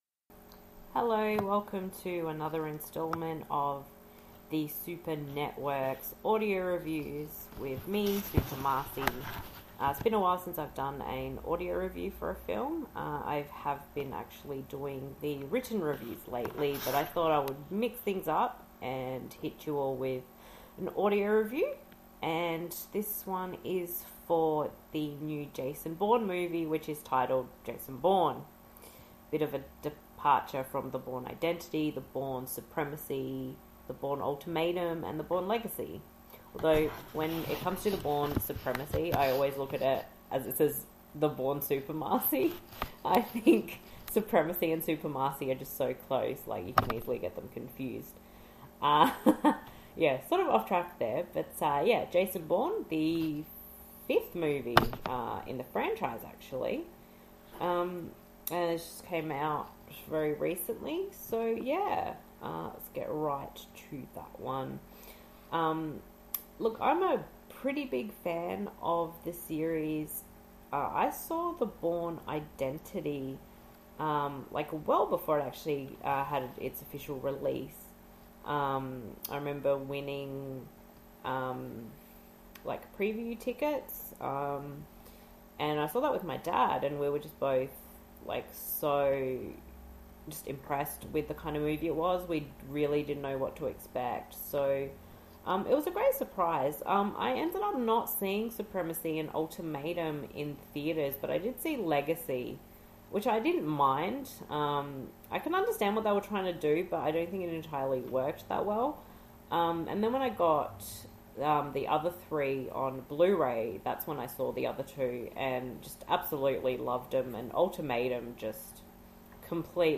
[Audio Review] Jason Bourne (2016)
jason-bourne-audio-review.mp3